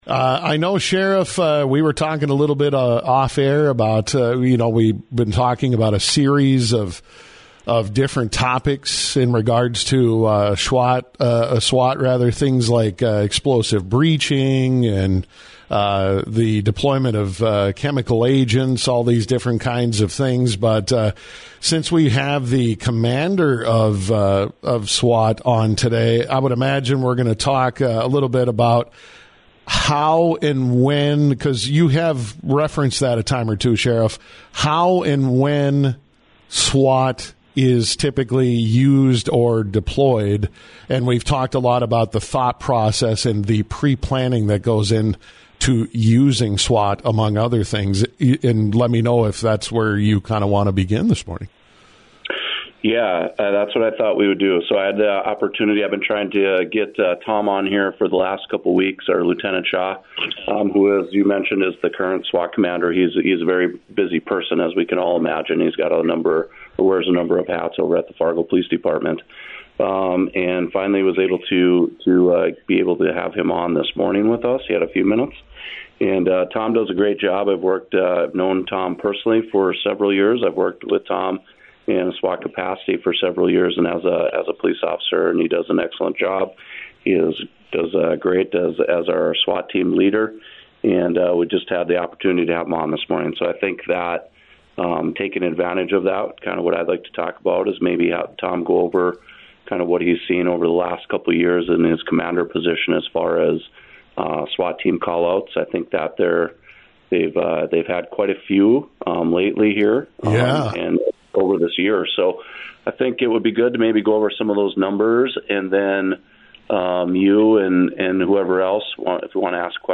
Cass County Sheriff Jesse Jahner said an increase in SWAT response is also due to Fargo-Moorhead’s growing population, mental health issues, drug use and people carrying guns during the transportation of drugs or while selling them.